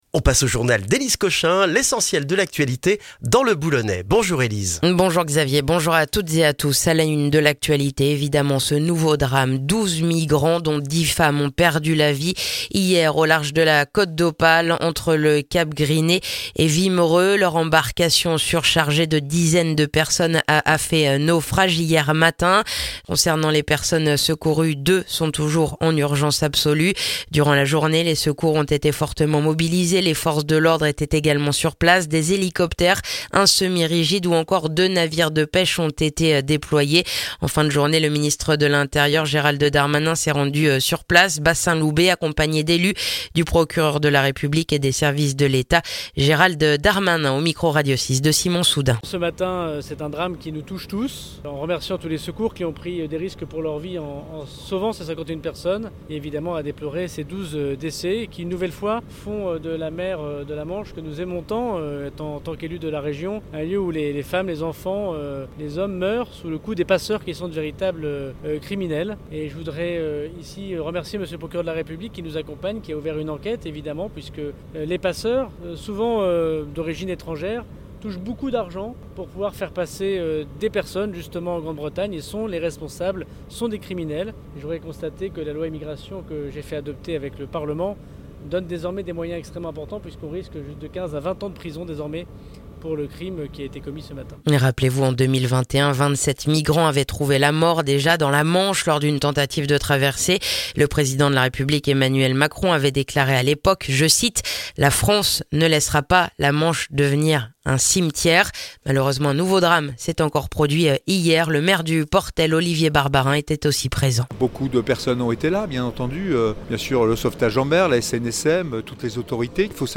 Le journal du mercredi 4 septembre dans le boulonnais